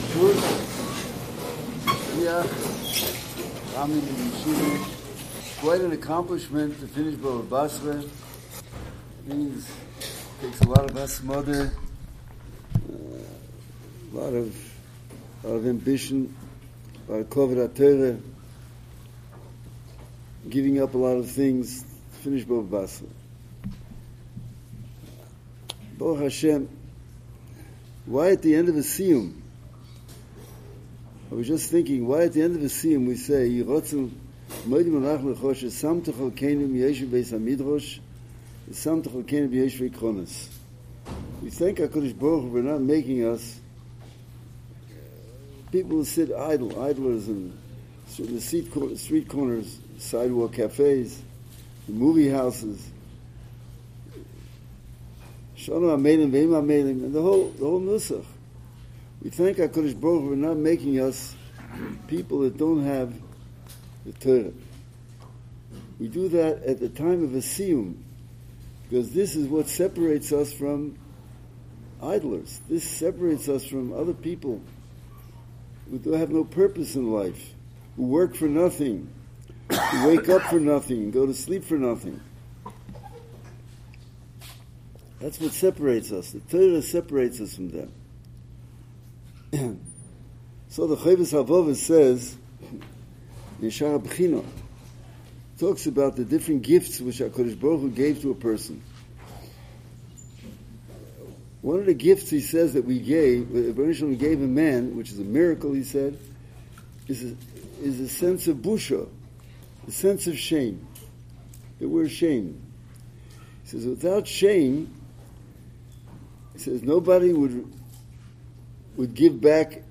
This week the Yeshiva celebrated the siyum on Baba Basra as we approach the end of the zman. The Rosh Hayeshiva shared divrei chizzuk for the summer and spoke about Gadlus HaAdam.